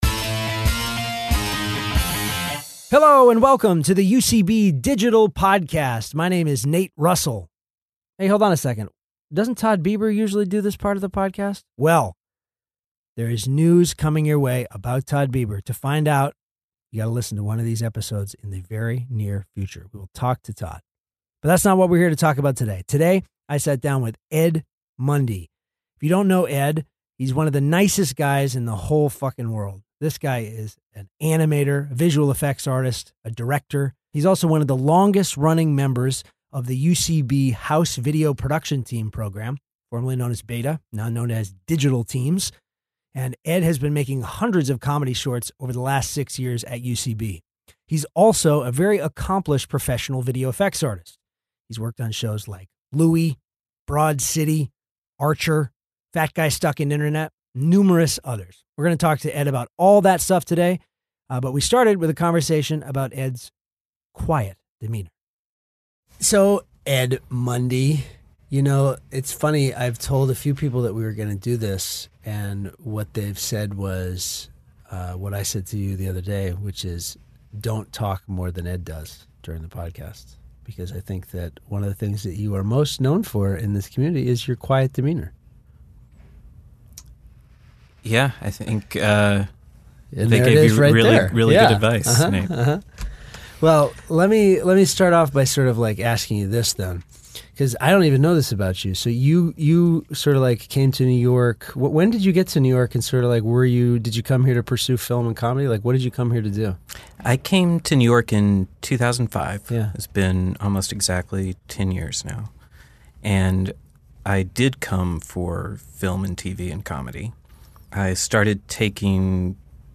Recorded at UCB Comedy studios in NYC.